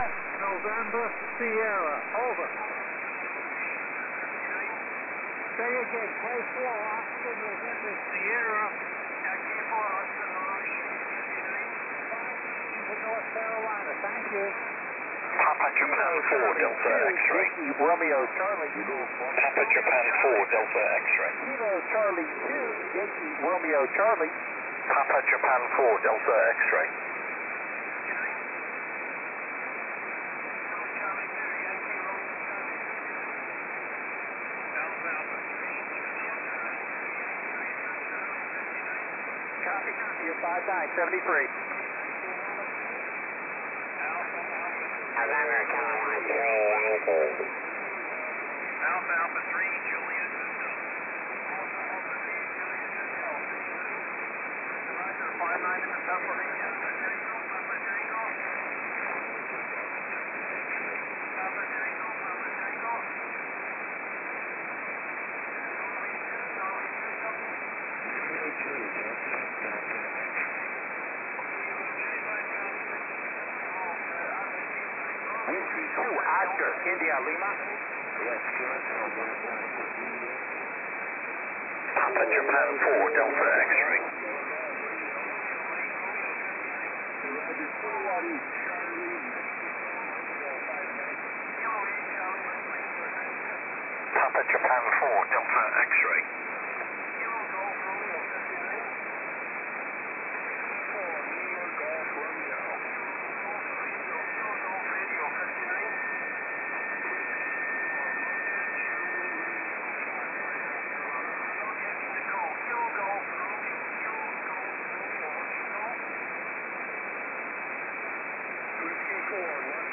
TX7EU 10SSB wrkg NA